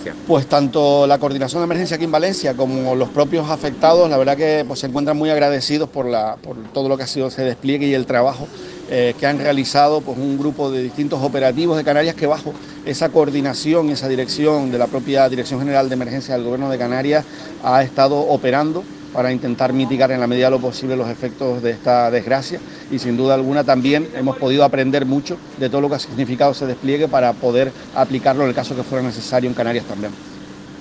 Marcos-Lorenzo-viceconsejero-de-Emergencias.mp3